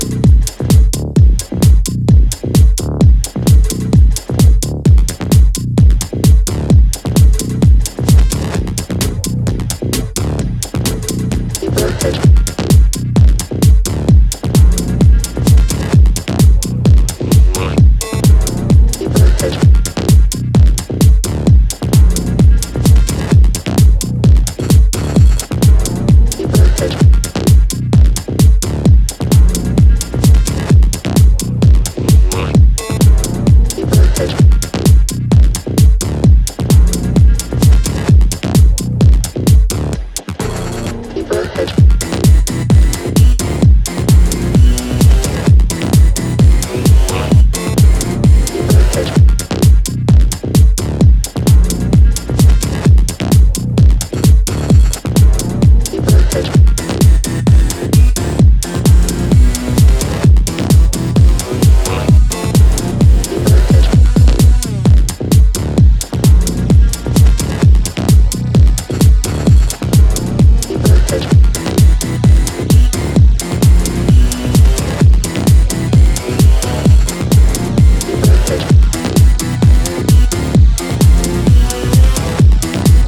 bouncing, elastic